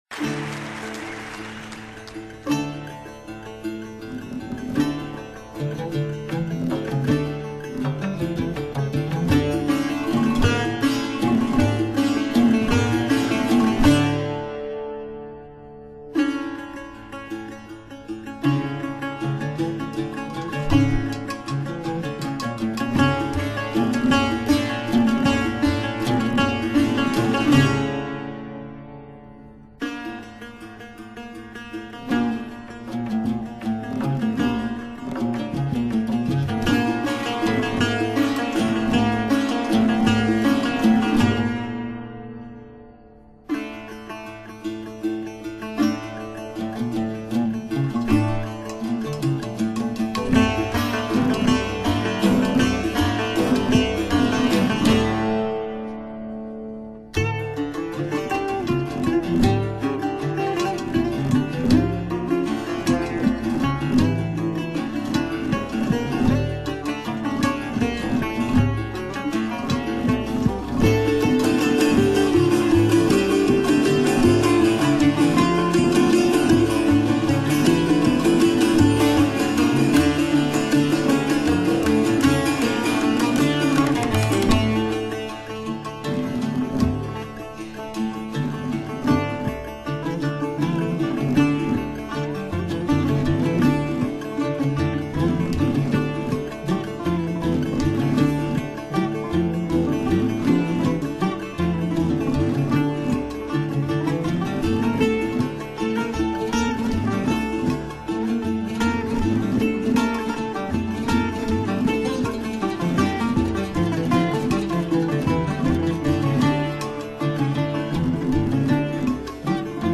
شورانگيز
كوزه
دودوك